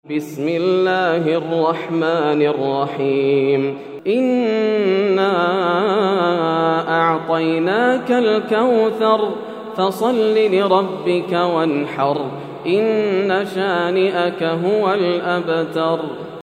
سورة الكوثر > السور المكتملة > رمضان 1431هـ > التراويح - تلاوات ياسر الدوسري